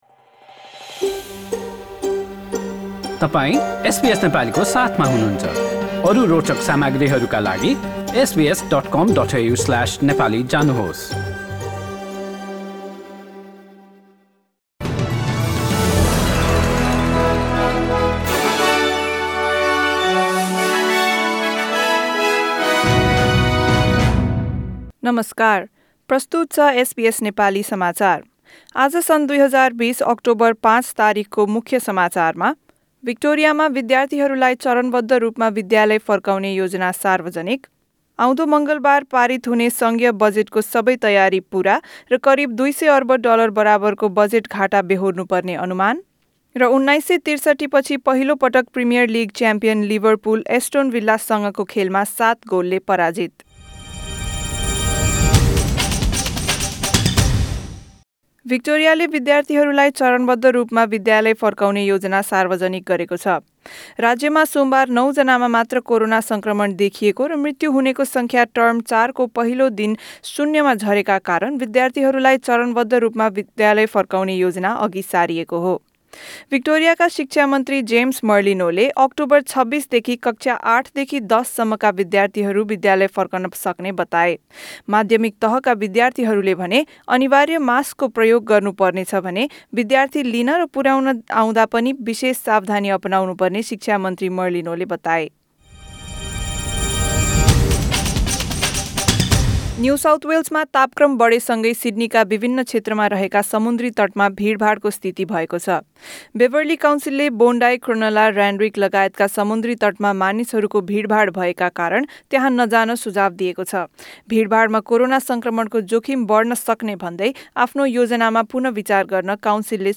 Listen to the latest news headlines in Australia from SBS Nepali radio - in this bulletin:All Victorian students are heading back to the classroom, as the state government prepares for a staged return to school.